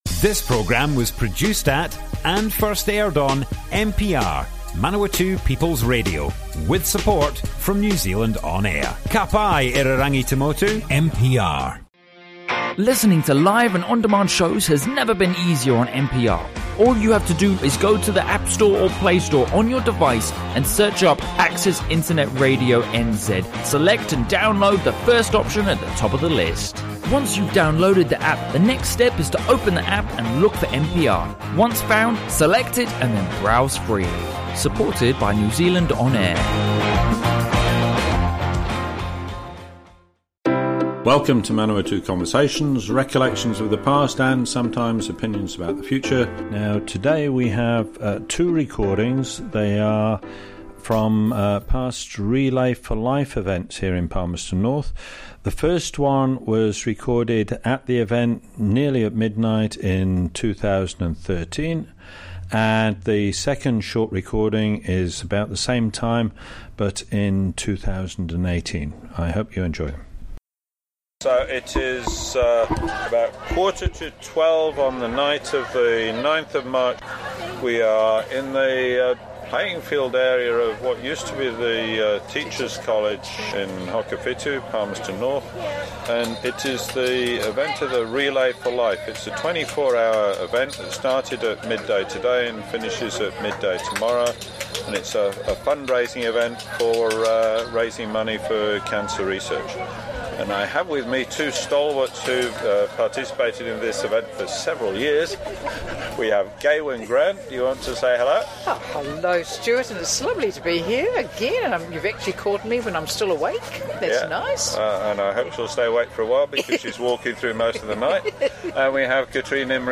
00:00 of 00:00 Add to a set Other Sets Description Comments Relay for Life - Manawatu Conversations More Info → Description A broadcast on Manawatu People's Radio, 1 January, 2019. Relay for Life, Rotary Club of Terrace End team participants describe their experience.